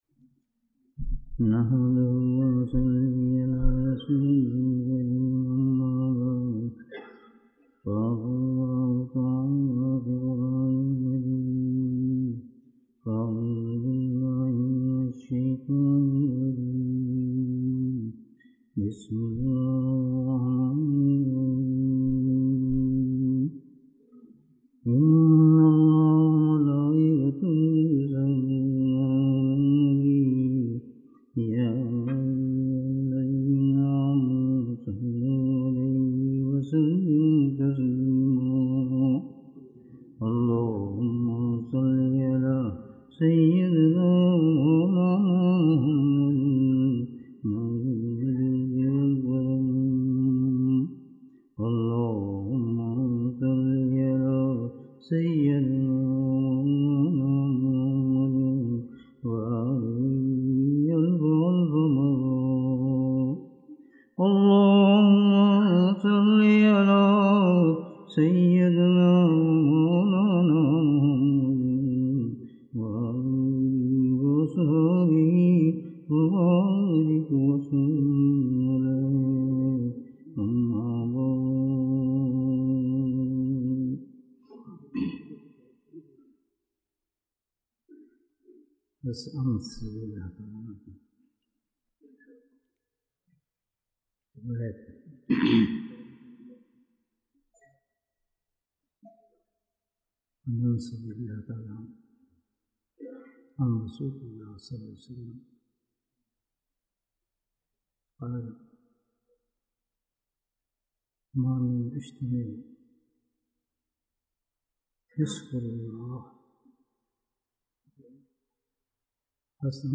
Ijtema Annual Majlis-e-Dhikr 2022 Bayan